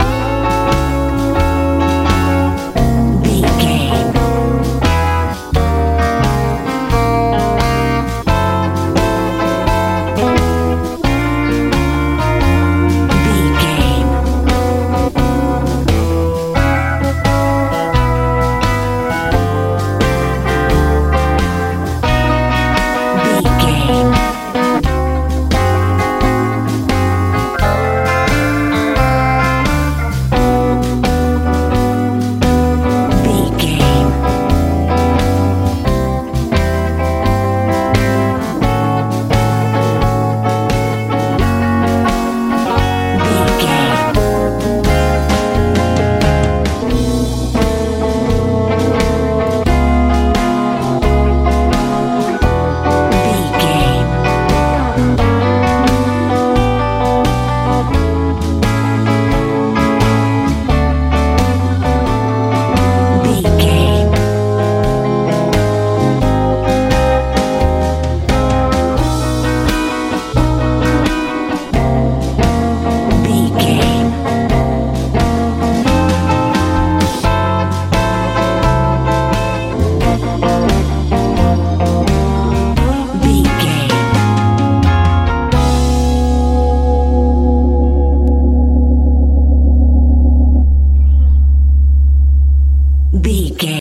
blues feel
Ionian/Major
F♯
groovy
funky
organ
electric guitar
bass guitar
drums
southern